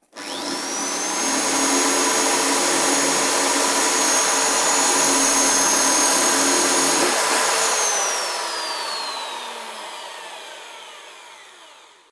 Taladradora de pared
taladro
Sonidos: Industria